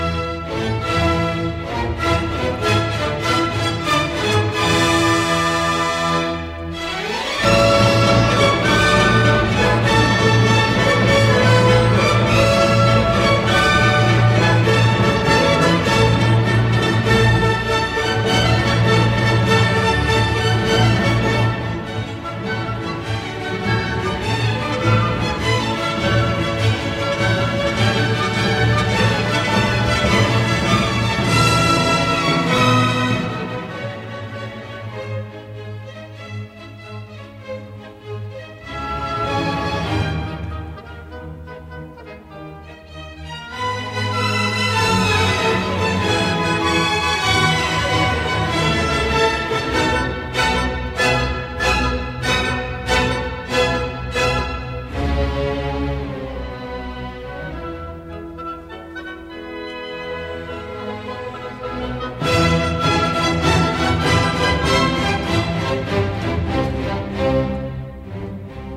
Violin: Beethoven: Symphony 7 violin excerpt from mvt.